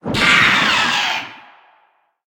File:Sfx creature seamonkey death 01.ogg - Subnautica Wiki
Sfx_creature_seamonkey_death_01.ogg